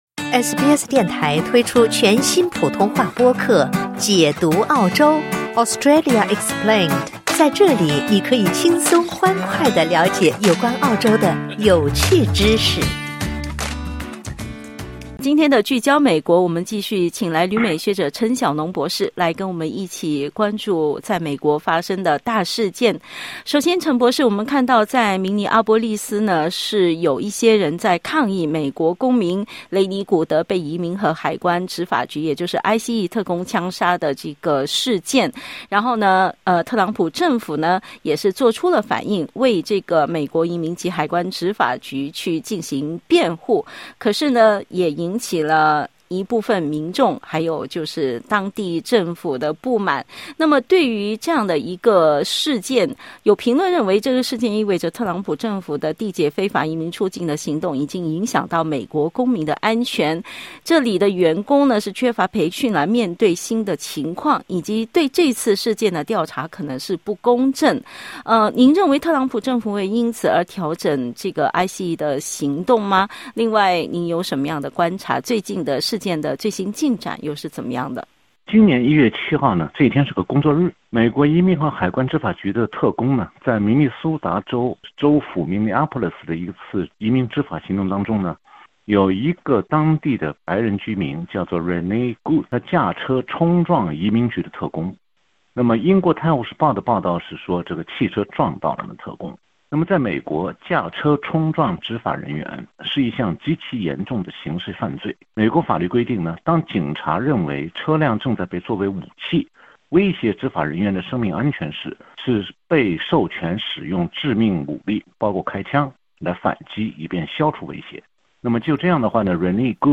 点击音频收听详细内容 采访内容仅为嘉宾观点 欢迎下载应用程序SBS Audio，订阅Mandarin。